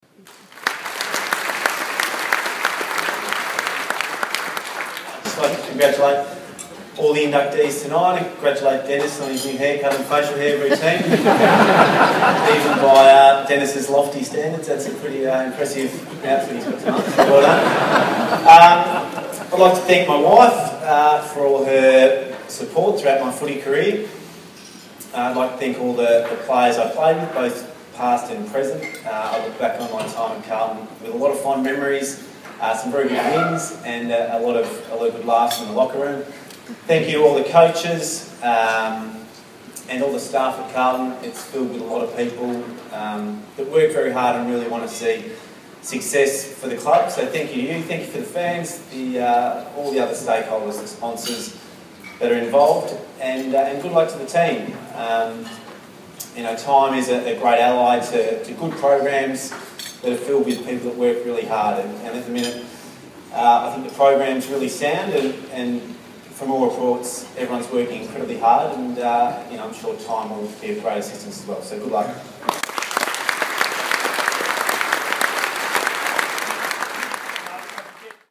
Blues great Chris Judd addresses the crowd after being awarded Life Membership of the Carlton Football Club.